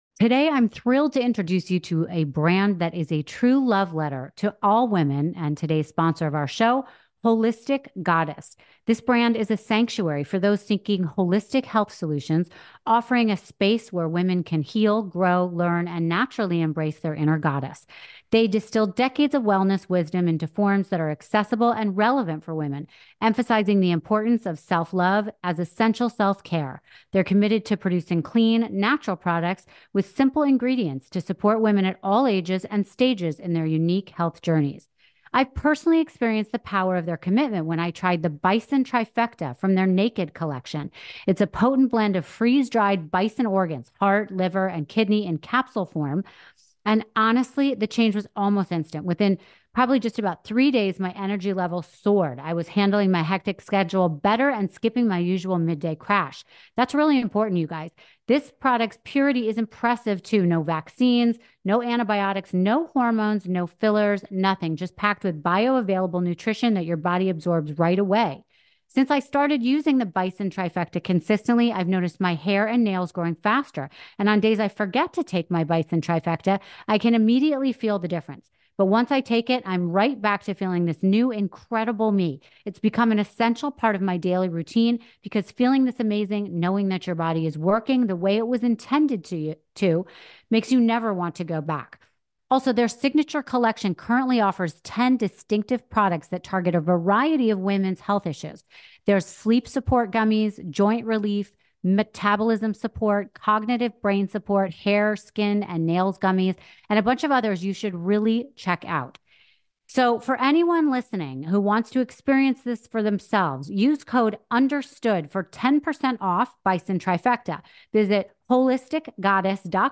Sean Spicer, former Secretary for Donald Trump, sat down with Rachel to discuss the Supreme Court ruling on immunity that came down this morning and its impact on both Trump and Biden. He also breaks down his thoughts on the presidential debate and the path going forward for both candidates. Spicer gives his predictions for Trump's VP pick and the presidential election of 2024 as a whole.